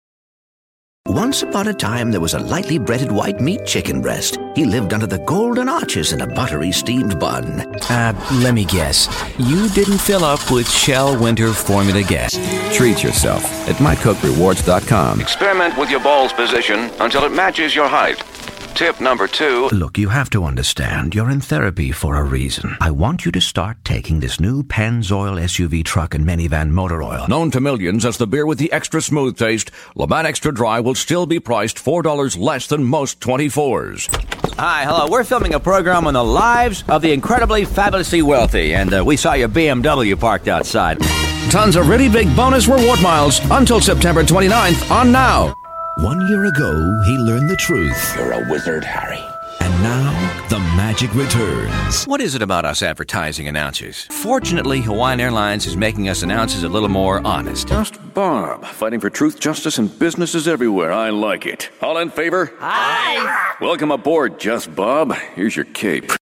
David Kaye - Commercial Demo